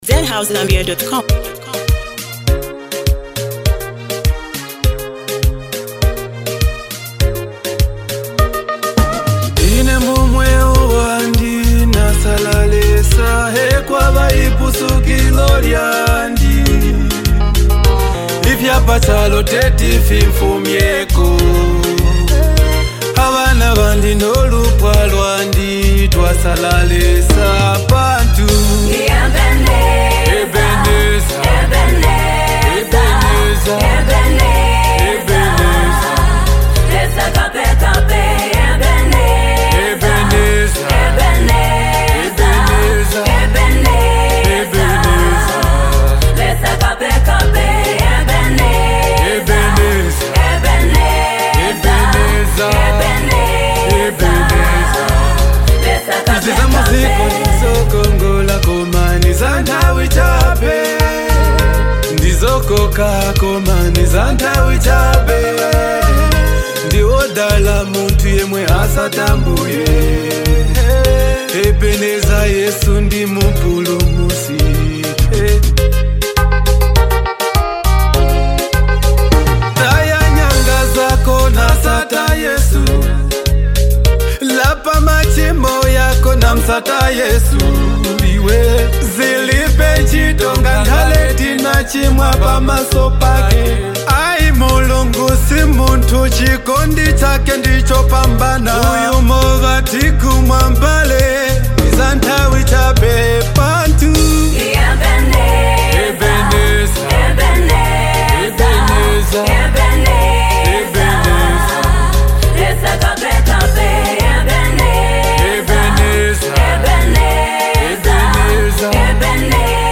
a powerful song of gratitude and testimony.